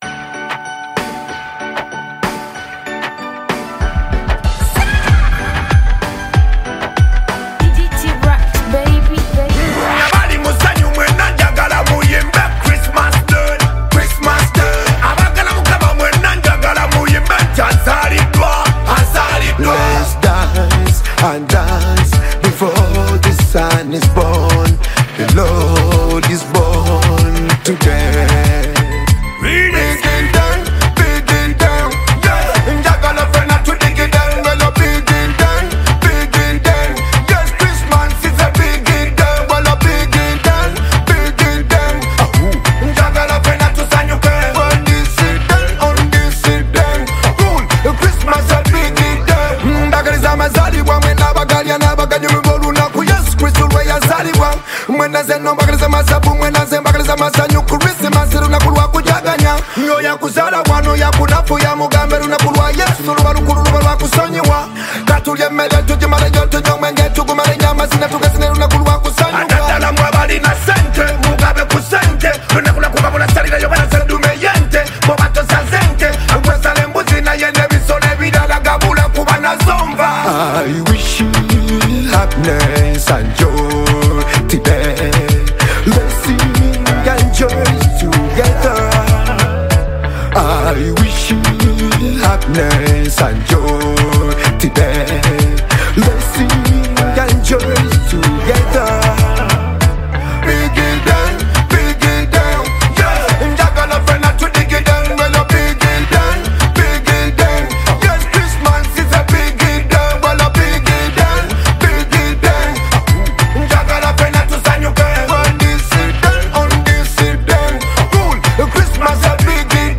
South African singer and songwriter